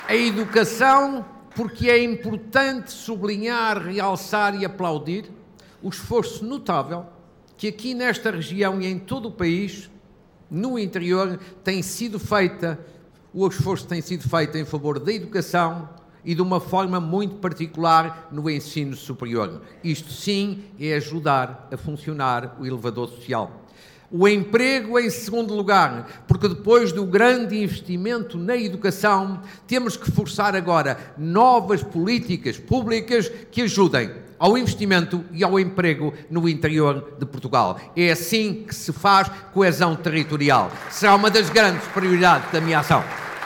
No discurso que assinalou o arranque da candidatura, Marques Mendes deixou uma promessa clara. Caso venha a ser eleito Presidente da República, pretende implementar presidências abertas e começar precisamente por Trás-os-Montes: